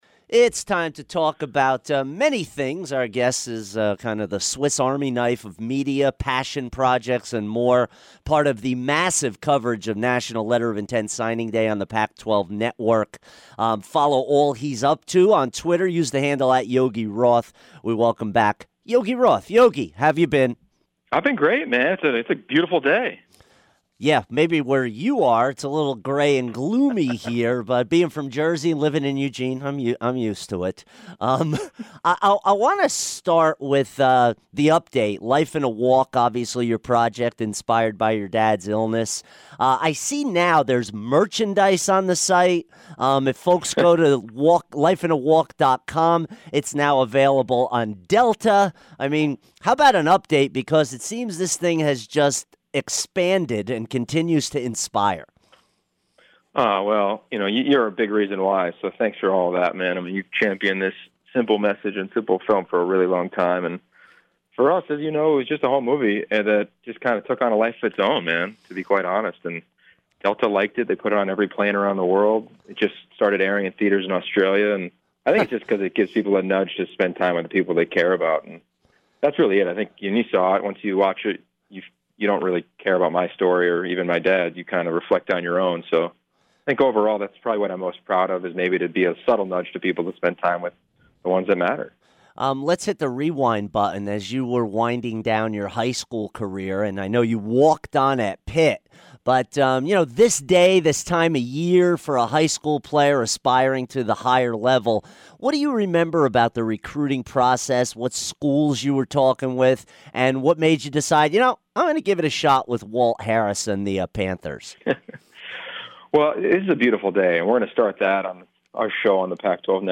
Yogi Roth Interview 2-1-17